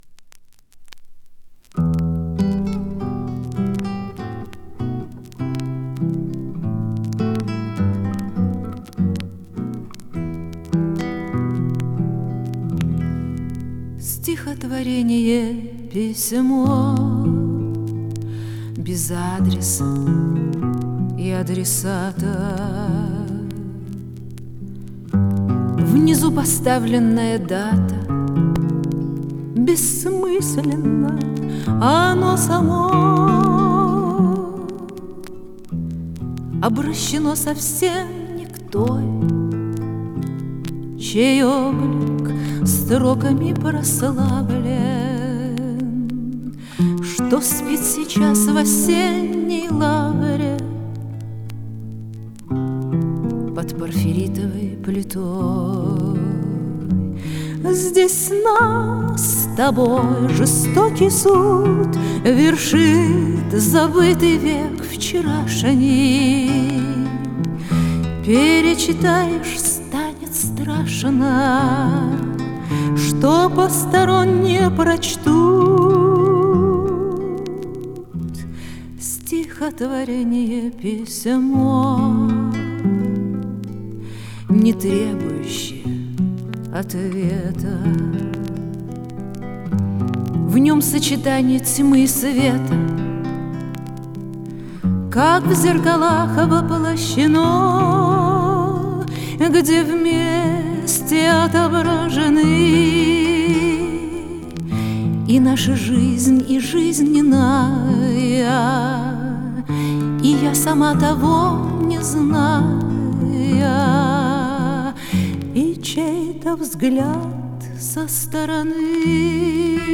Жанр: Romance